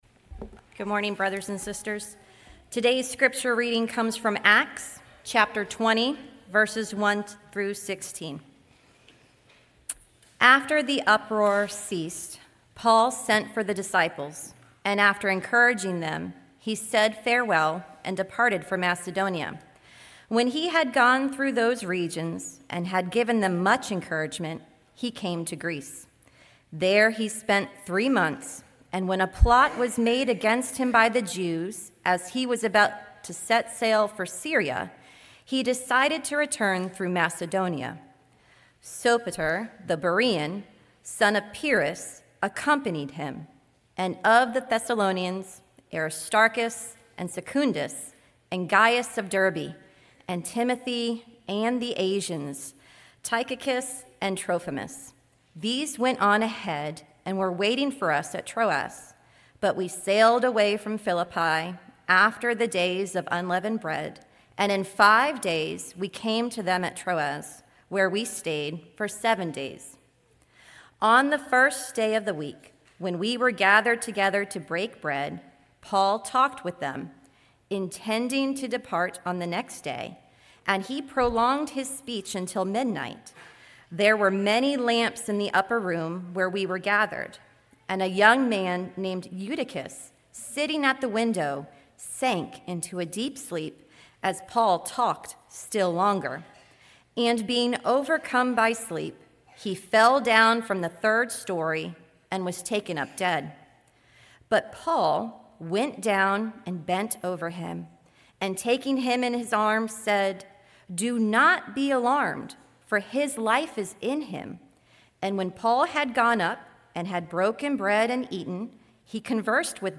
sermon7.13.25.mp3